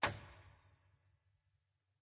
Re-recorded the piano instrument to sound better!